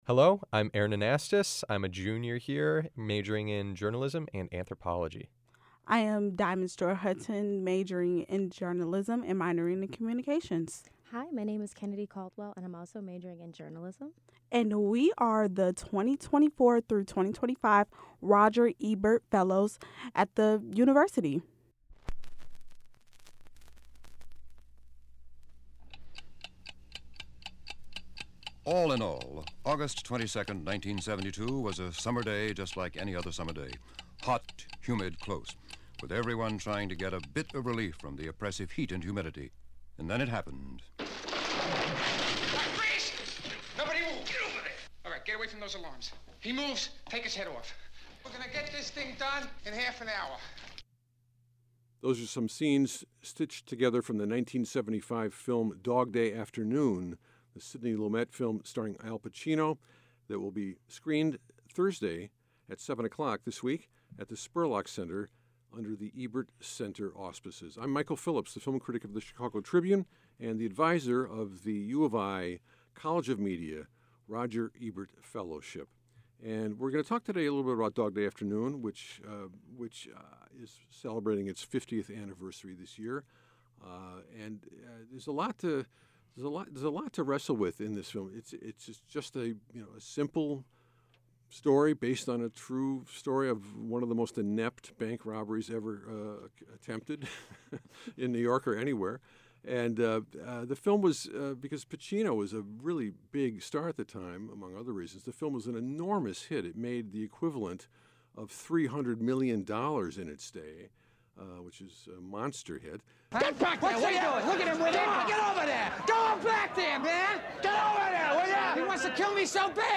2025 Roger Ebert Fellows discuss the move Dog Day Afternoon in the WILL studios.